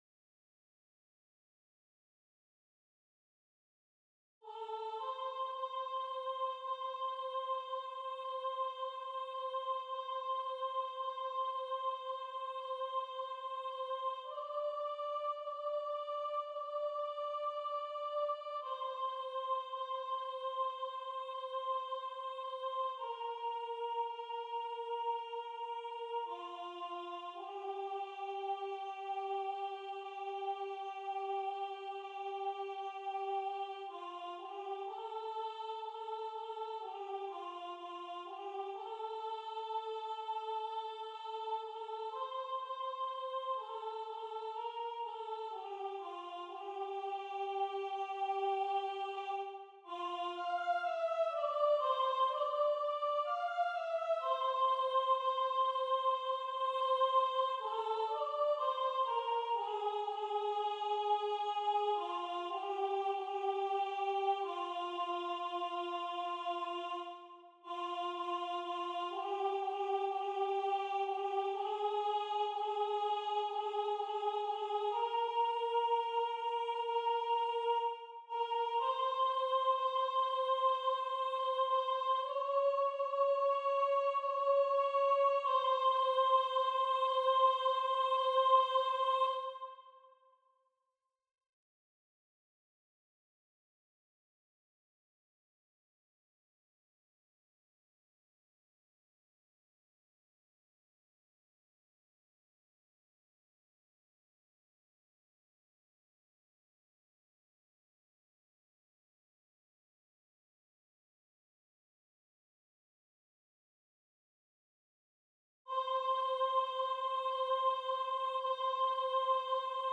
Soprano 1